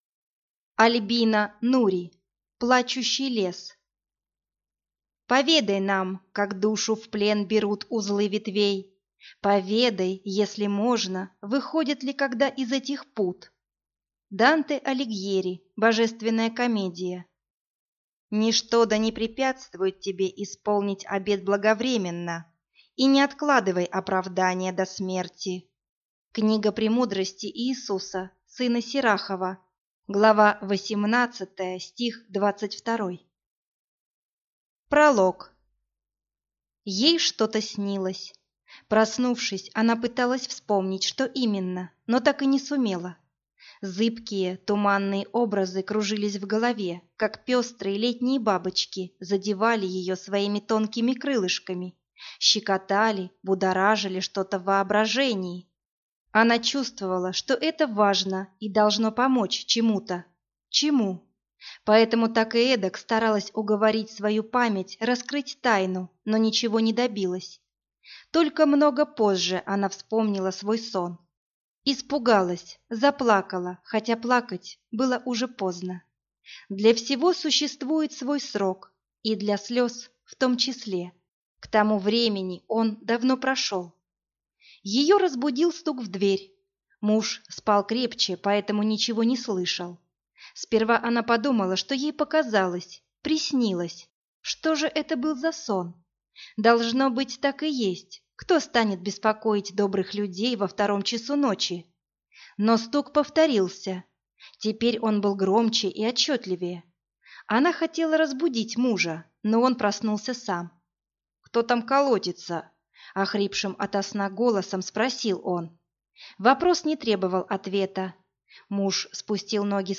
Аудиокнига Плачущий лес | Библиотека аудиокниг
Прослушать и бесплатно скачать фрагмент аудиокниги